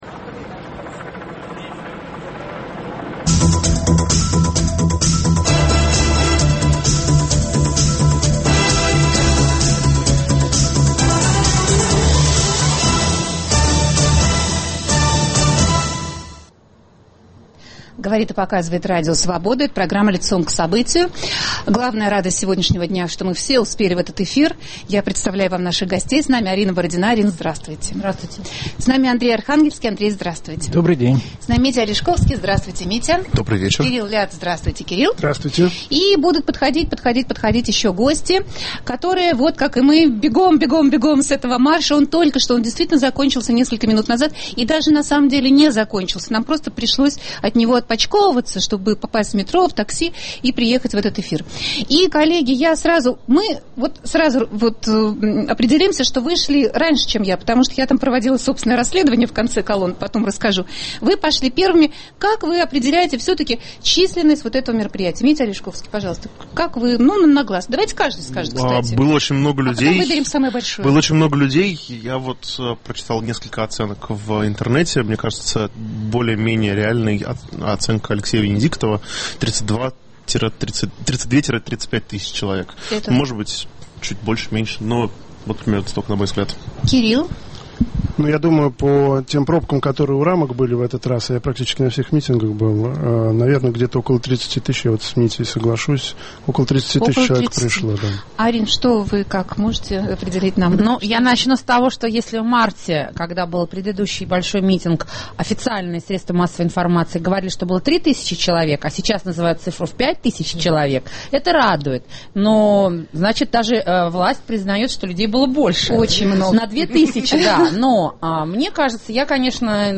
Чего можно добиться такими акциями? Обсуждение в прямом эфире.